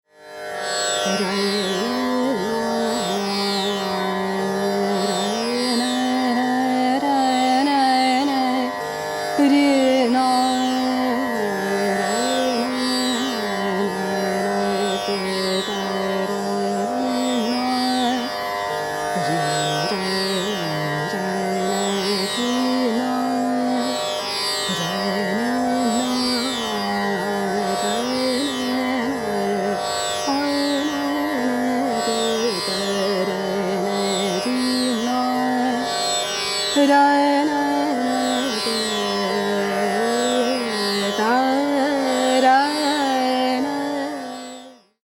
S-R-G-M-D-N-S
[alap, e.g. 9:52] S(NS) G R S N S G G G (M)G (M)G (M)G, M\G G (GN)S (S)G G (G)N N S, (S)N (S)N S G G…
Jazz: Lydian (no 5th)
• Tanpura: Sa–Dha